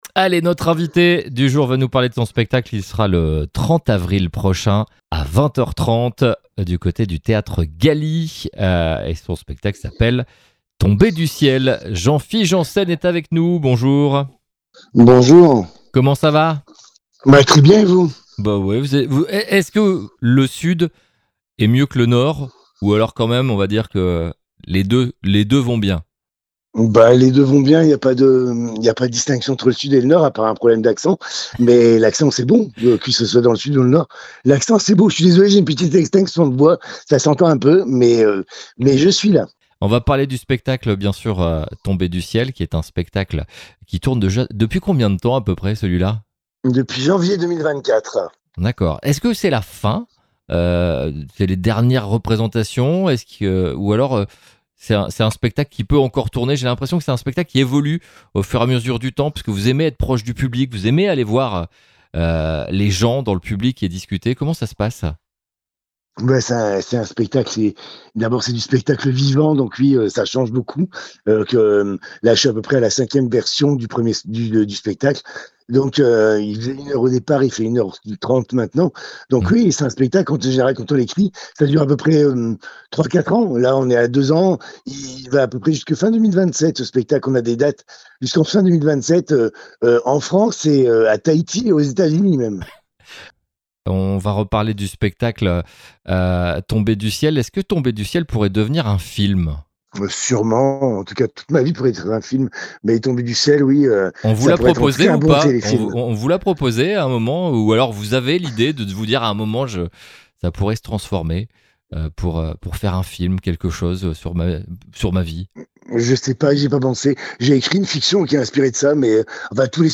Les interviews exclusifs de RCB Radio